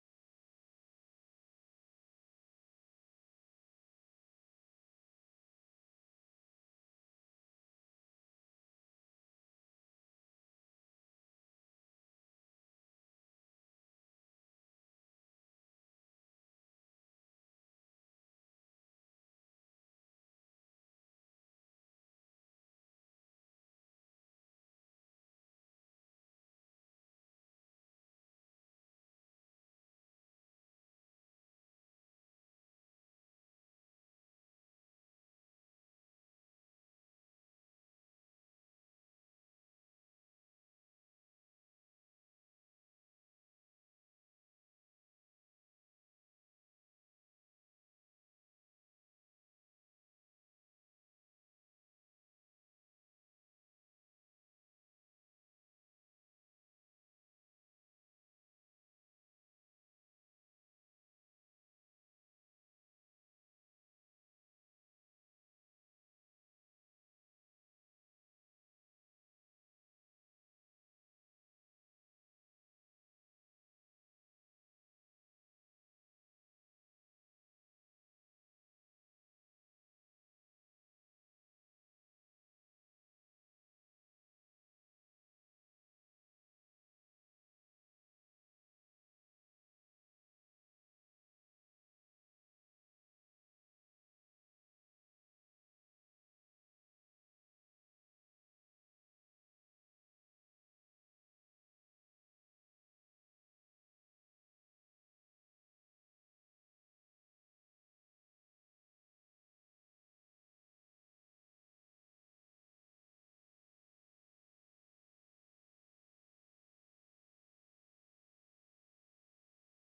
LIVE Morning Worship Service - Bread from Heaven
Congregational singing—of both traditional hymns and newer ones—is typically supported by our pipe organ.